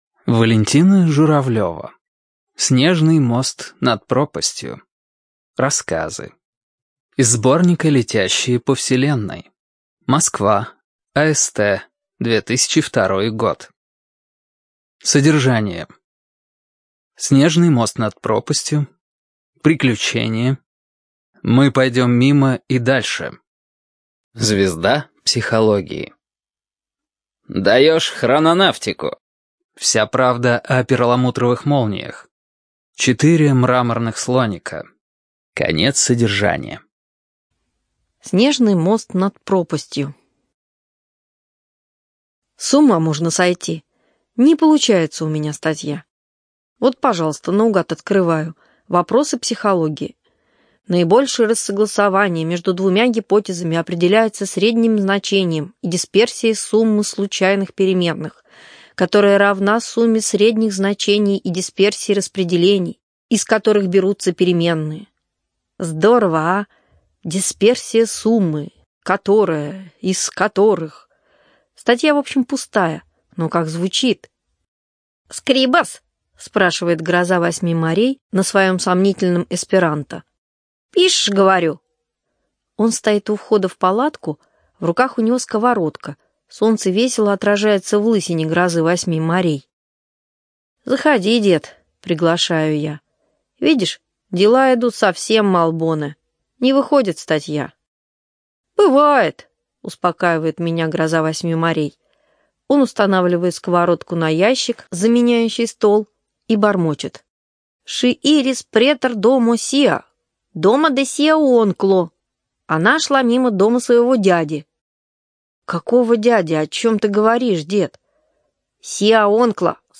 ЖанрФантастика
Студия звукозаписиЛогосвос
Прекрасная озвучка.